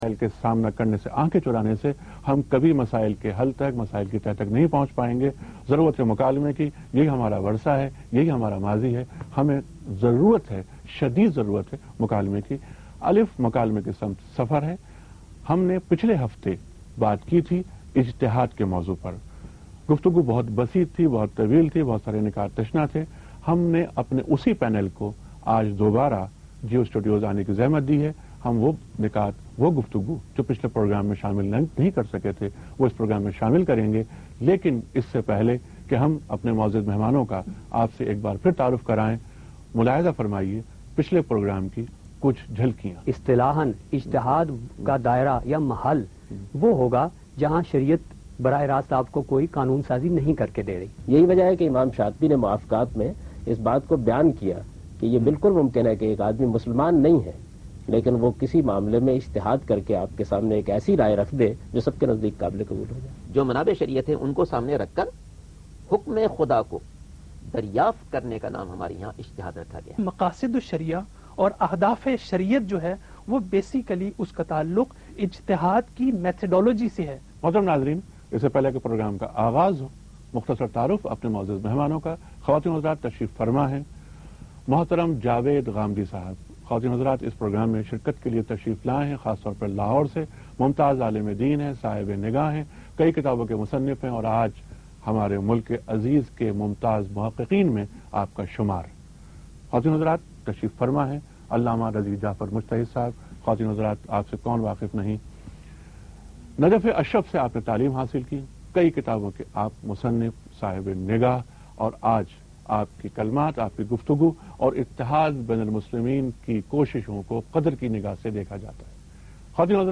Detailed discussion about divorce with Javed Ahmad ghamidi and other scholars during a talk show Alif on Geo News